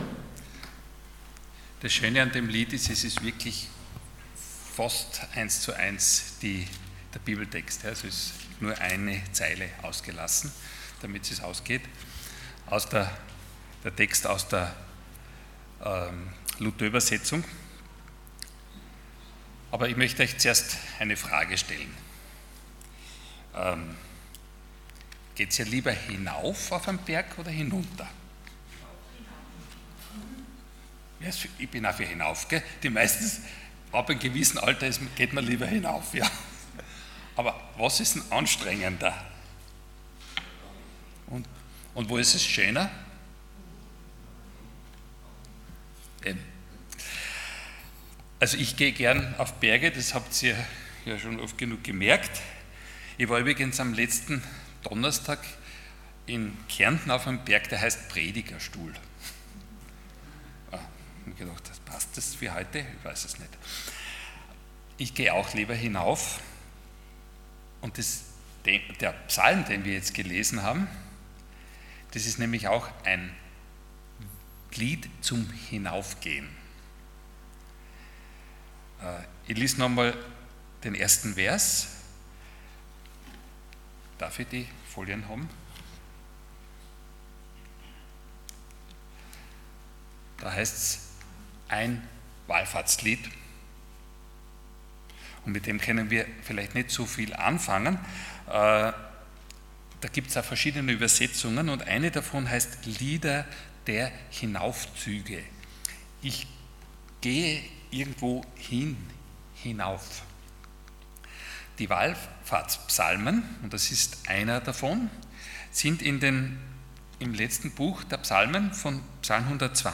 Passage: Psalm 130:1-8 Dienstart: Sonntag Morgen